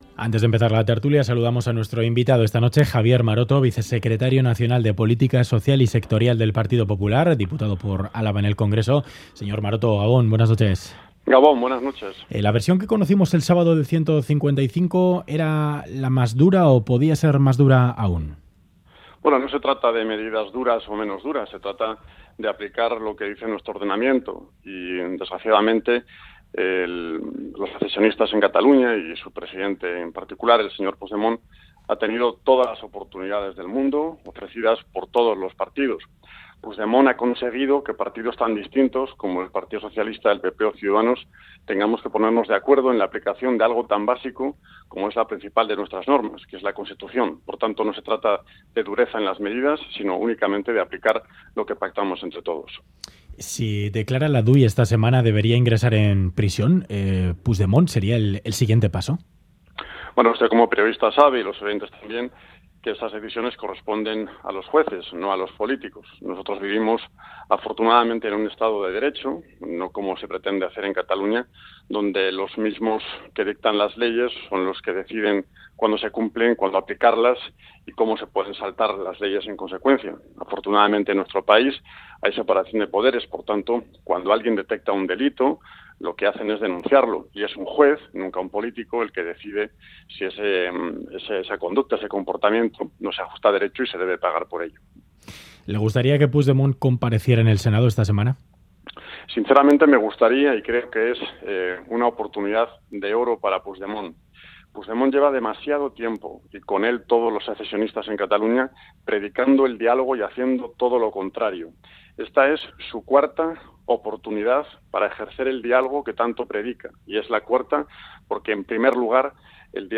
Audio: Entrevistado en Ganbara Javier Maroto, vicesecretario del PP, considera que Euskadi tiene un nivel de autogobierno como el que no existe en Europa.